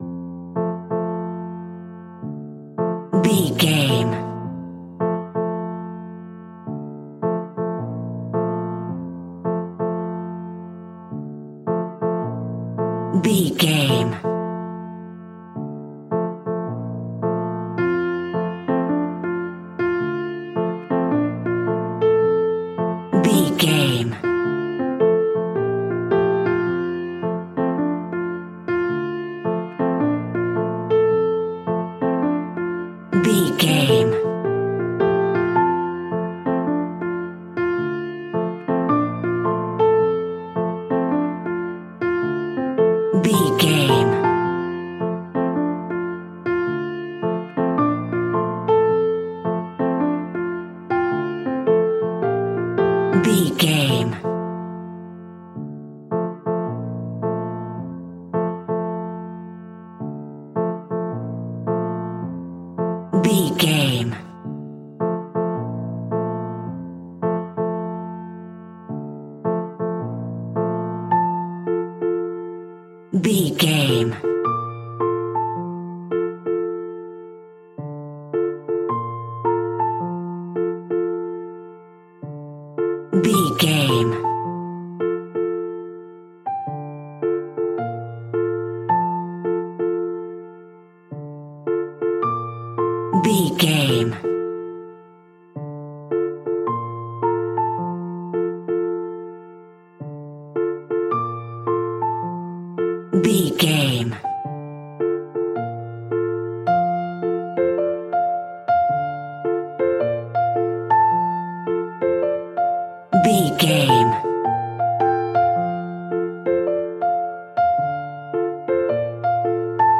Ionian/Major
Slow
relaxed
tranquil
synthesiser
drum machine